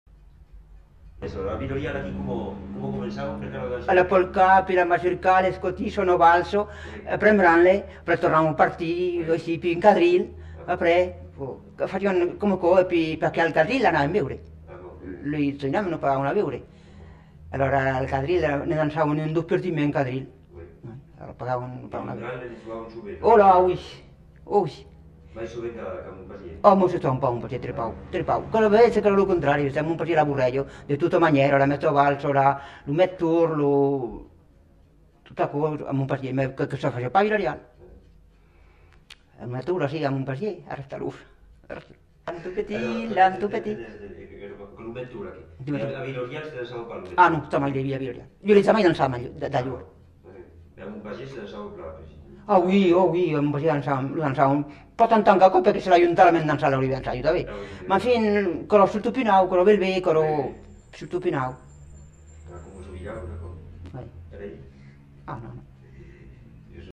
Lieu : Castillonnès
Genre : témoignage thématique